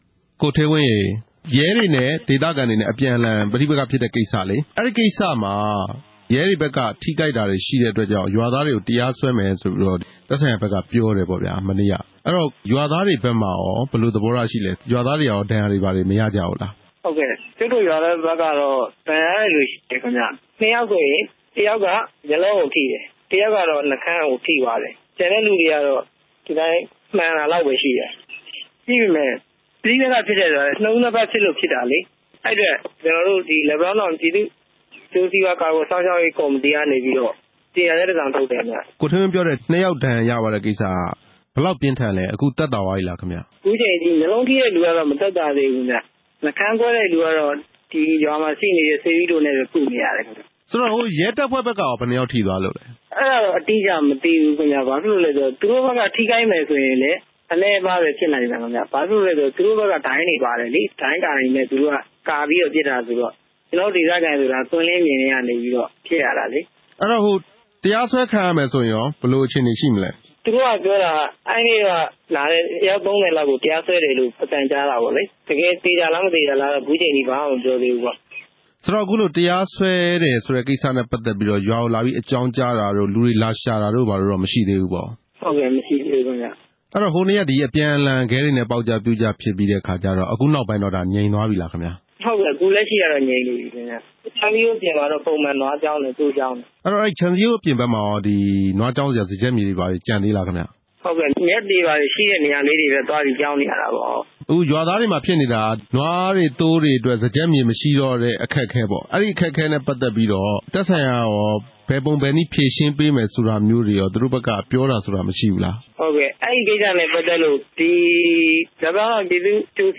လက်ပံတောင်း ပဋိပက္ခ ရွာသားတစ်ဦးနဲ့ မေးမြန်းချက်
မေးမြန်းခန်း